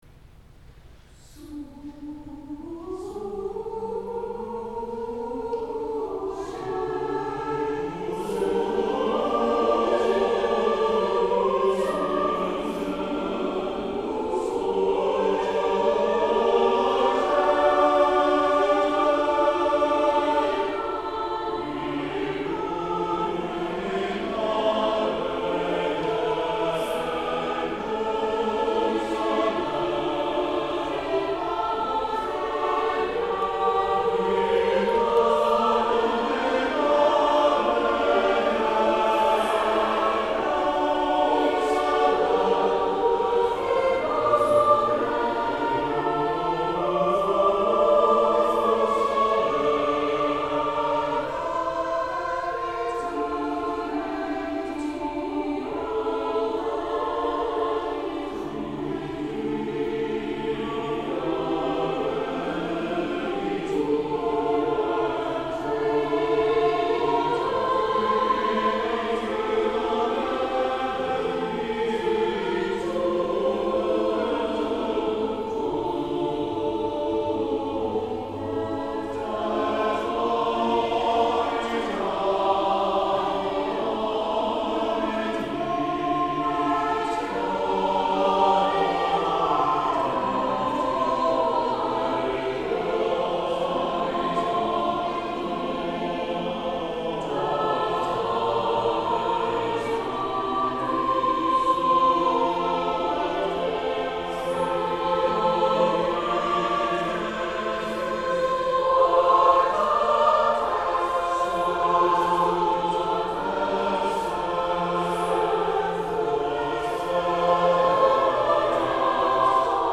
Festival of Nine Lessons and Carols
Cathedral Choir
Carol - William Byrd - Surge Illuminare